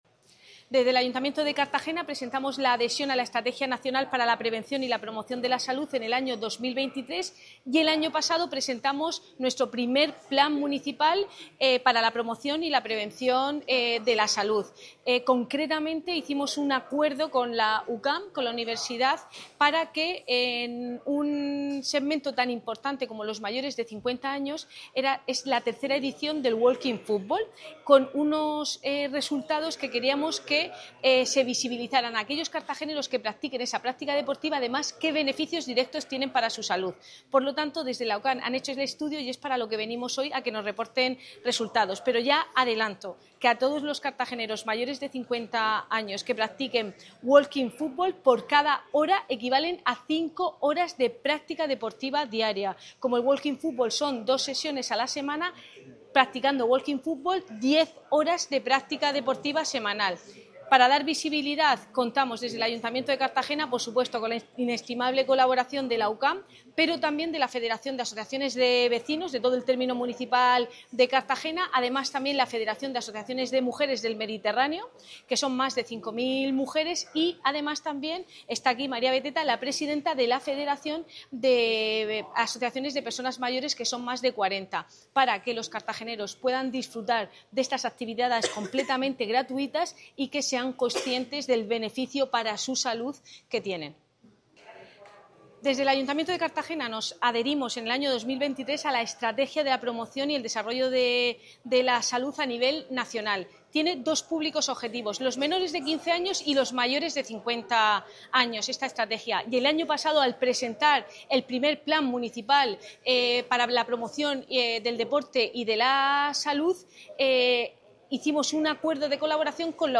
Declaraciones de la edil Cristina Mora